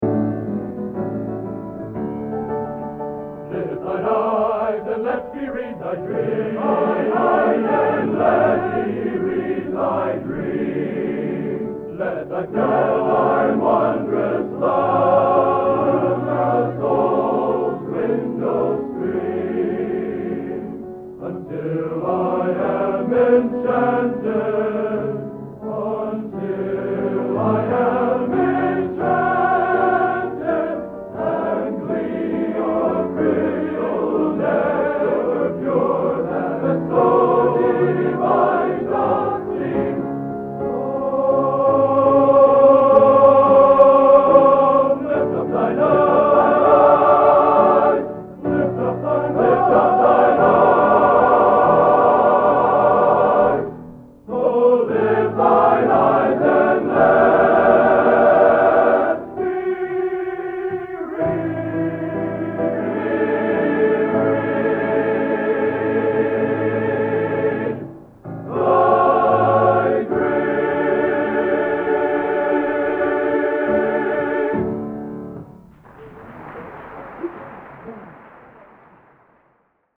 Location: West Lafayette, Indiana
Genre: Sacred | Type: End of Season